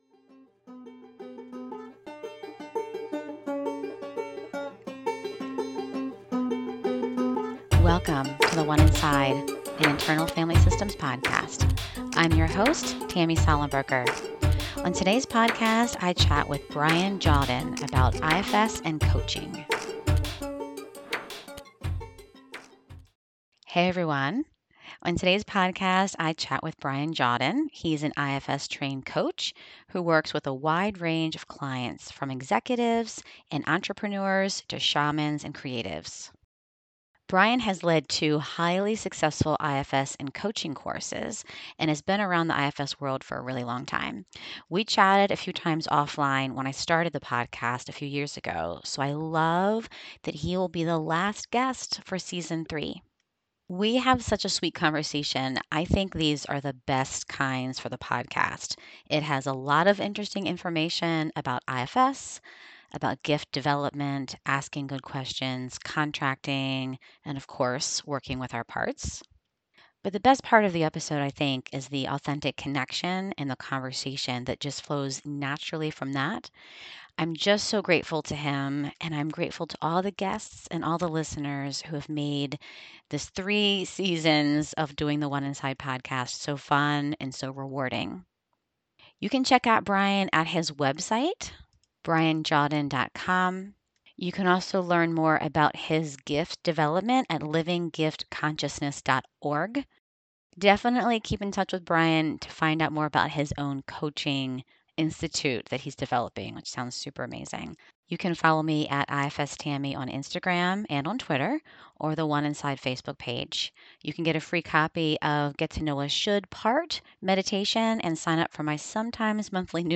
We have a sweet conversation- the best kind I think for the podcast- it has a lot of interesting information about IFS, Gift Development, asking good questions, Contracting, working with Parts. But the best part is the authentic connection and the conversation that flows so naturally from that.